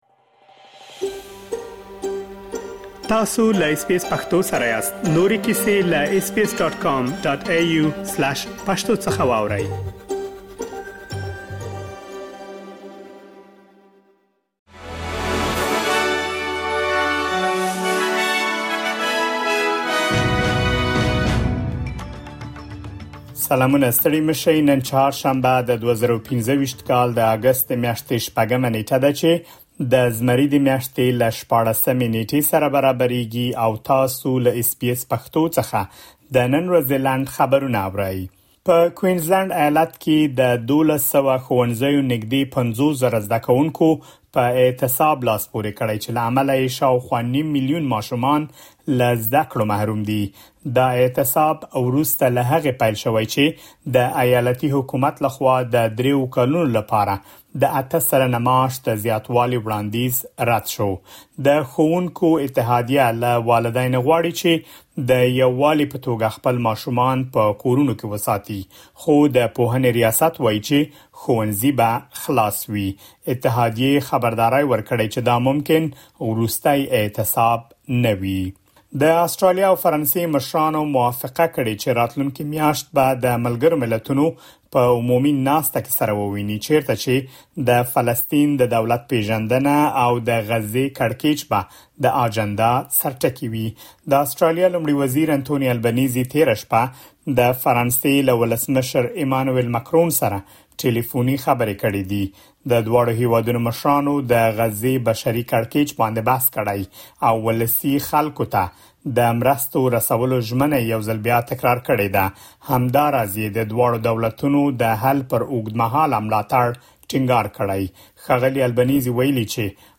د اس بي اس پښتو د نن ورځې لنډ خبرونه |۶ اګسټ ۲۰۲۵